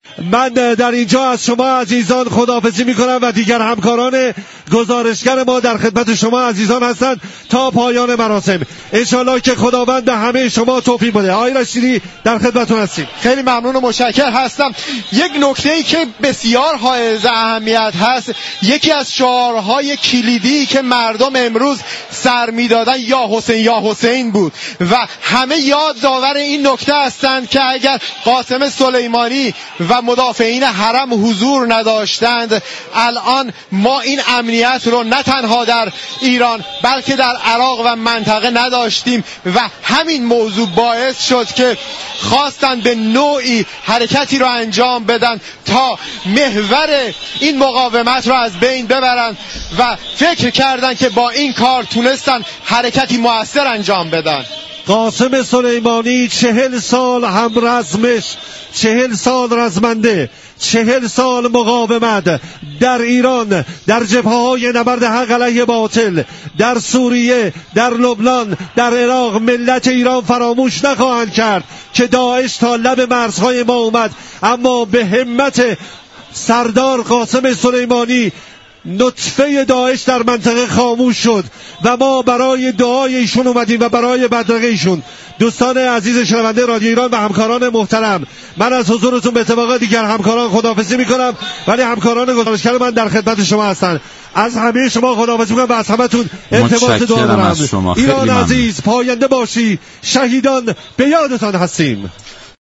در آیین بدرقه مردم با حاج قاسم سلیمانی، مردم با سردادن شعار «یا حسین» این نكته را به دنیا گوشزد می كنند كه امروز مردم ایران امنیت خود را مدیون رشادت های حاج قاسم و همرزمانش هستند.